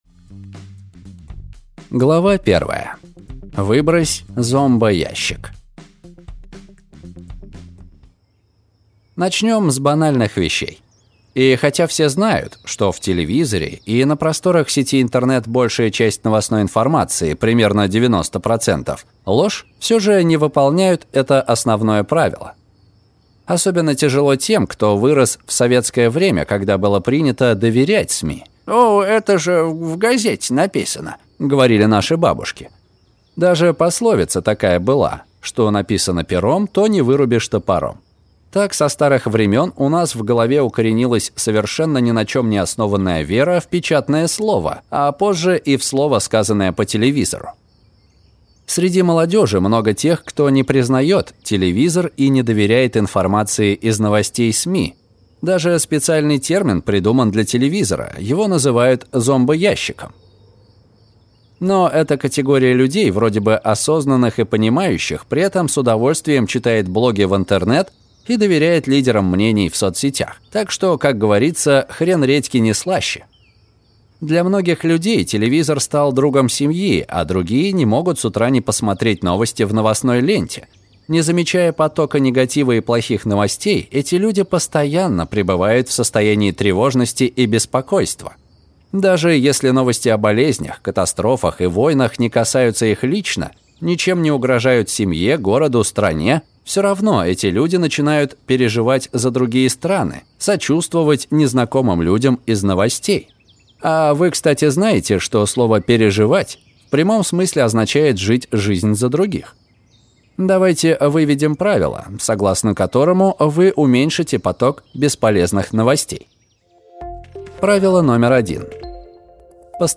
Аудиокнига 15 правил пофигизма: как перестать тревожиться и обрести спокойствие во время кризиса, пандемии и вооруженных конфликтов | Библиотека аудиокниг
Прослушать и бесплатно скачать фрагмент аудиокниги